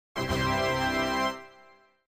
tadasoundeffect.ogg